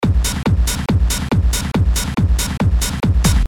技术或恍惚类型的循环
描述：没有小鼓，只有踢腿和帽子。基本但有效。
Tag: 140 bpm Techno Loops Drum Loops 590.80 KB wav Key : Unknown